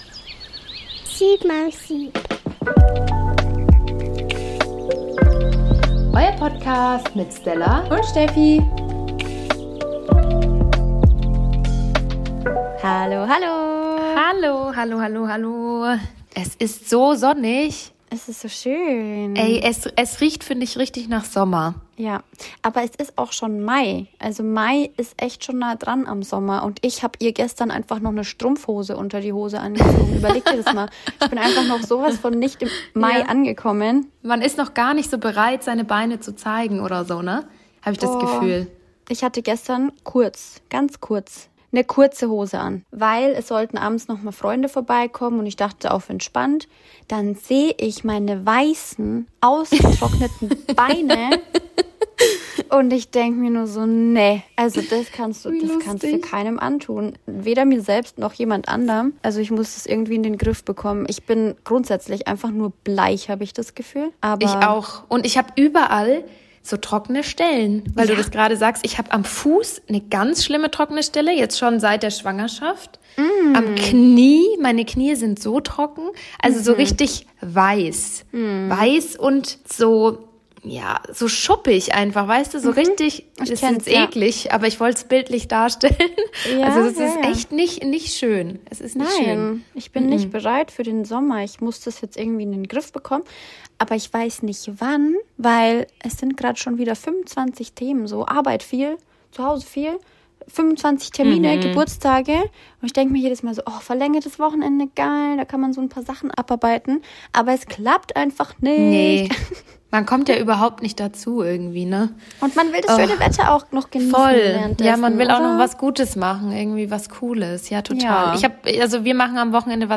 Die Mamas sind energiegeladen, gut gelaunt und auch für andere Dinge gibt es wieder mehr Platz als in den letzten Wochen.